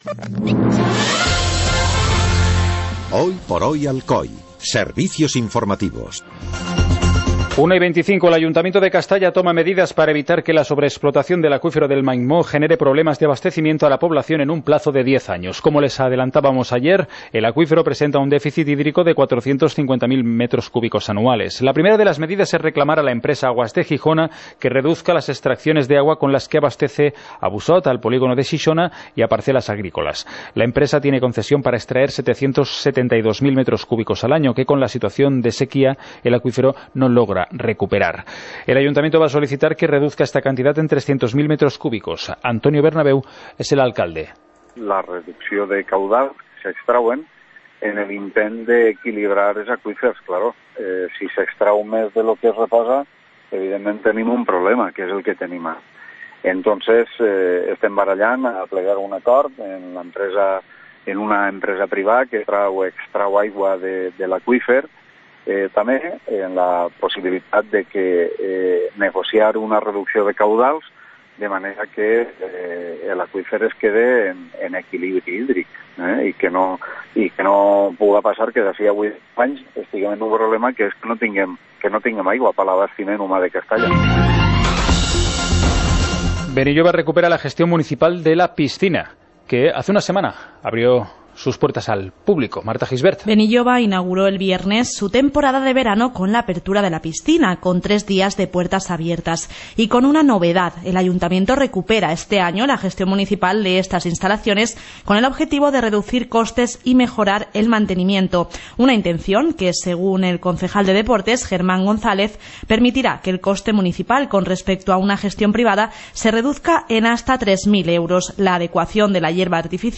Informativo comarcal - viernes, 01 de julio de 2016